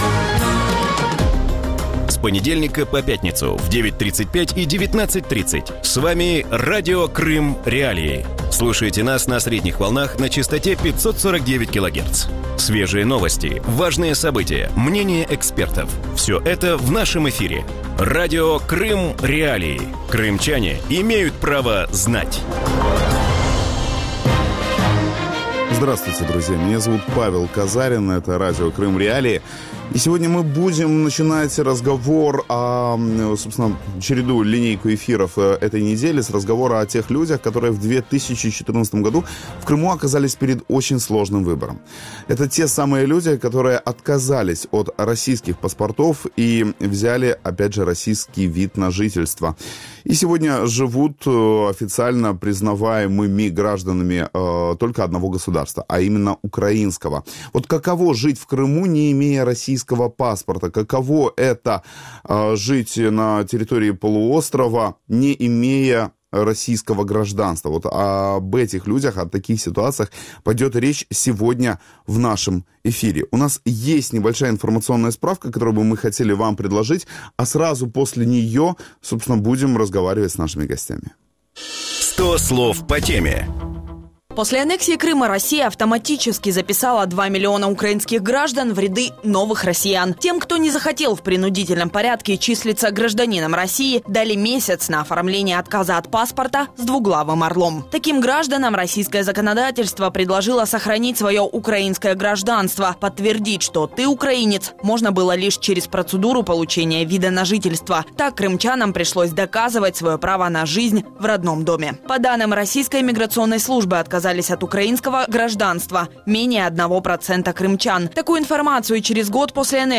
В утреннем эфире Радио Крым.Реалии говорят о том, как живется на полуострове тем, кто после российской аннексии отказался получать паспорт РФ.
С какими проблемами сталкиваются крымчане с ВНЖ и как Украина может помочь своим гражданам в Крыму? На эти вопросы ответят крымчане получившие ВНЖ, первый замминистра по вопросам временно оккупированных территорий Юсуф Куркчи, а также российская правозащитница, председатель комитета «Гражданское содействие» Светлана Ганнушкина.